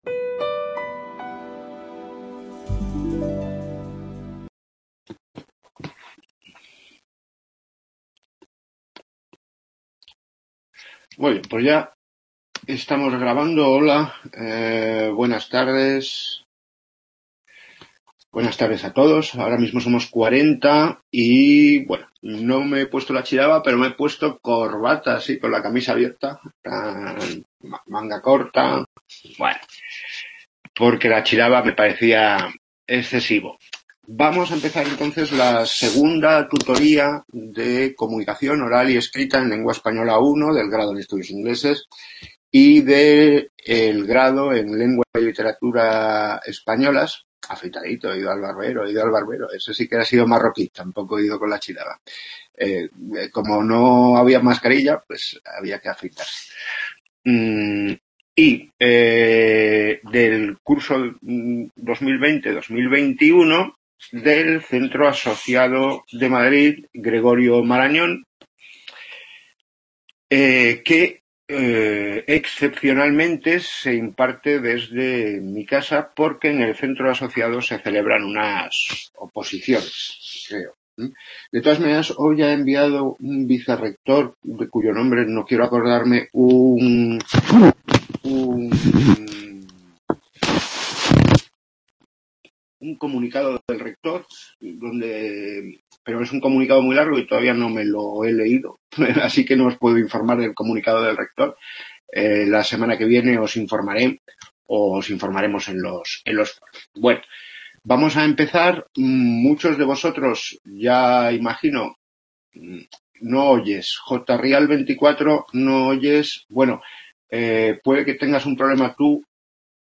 Segunda tutoría de COELEI (2020-2021) en Gregorio Marañón.